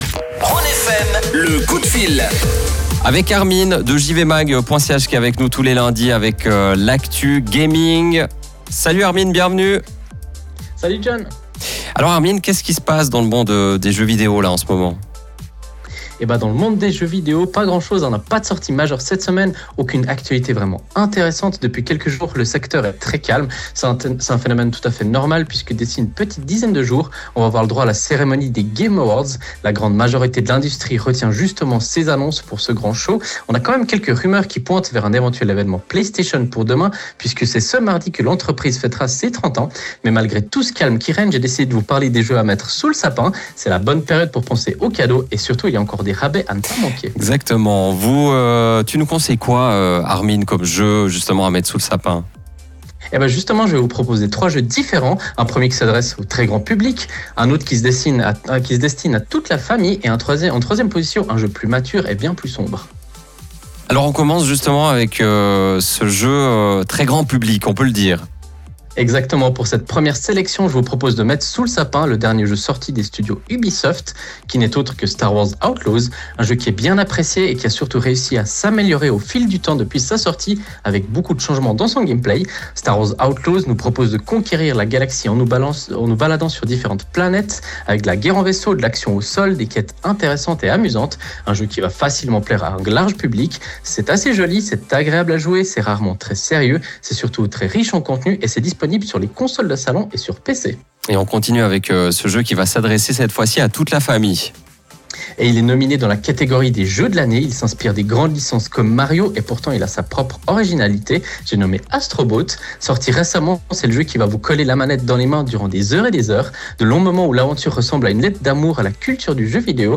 Comme tous les lundis, nous avons la chance de présenter notre chronique gaming sur la radio Rhône FM.
Vous pouvez réécouter le direct Rhône FM via le flux qui se trouve juste en haut de l’article.